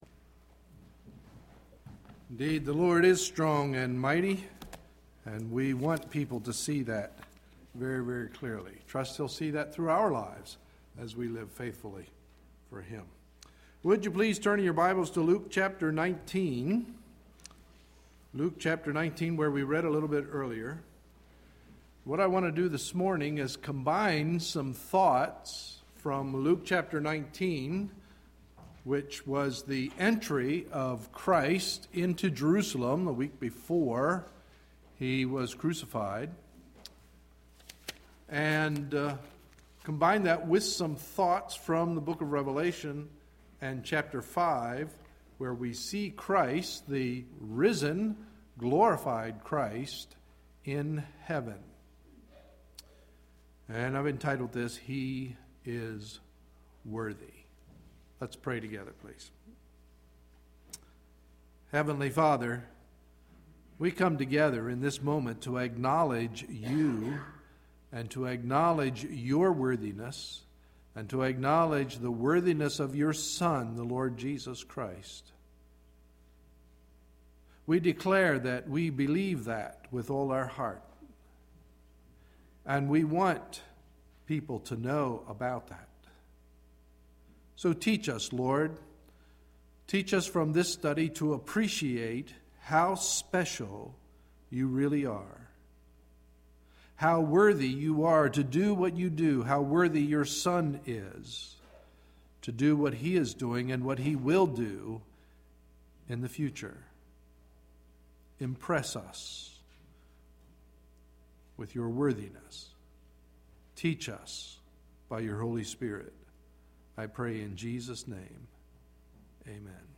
Sunday, April 17, 2011 – Morning Message